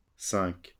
wymowa:
IPA[sɛ̃k] (zob. uwagi) ?/i